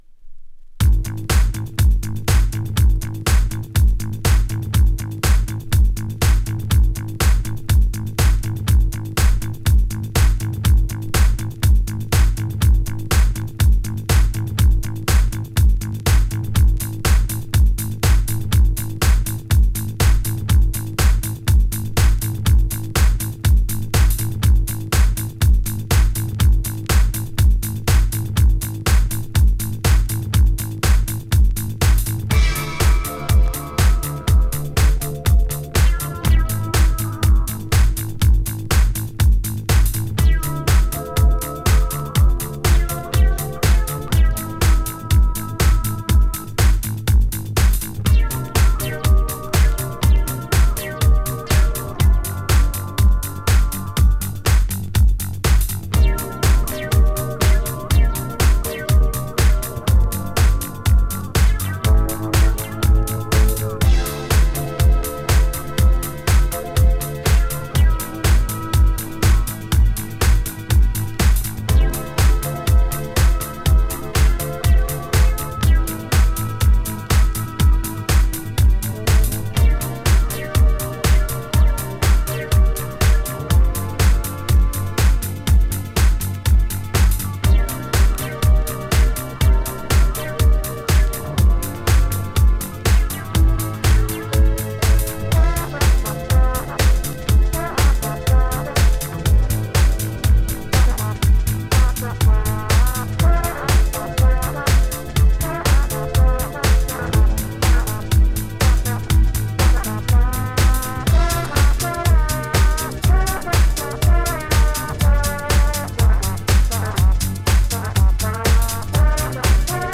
レアなカナダ・ディスコ、イタロをフロアユースにedit!!!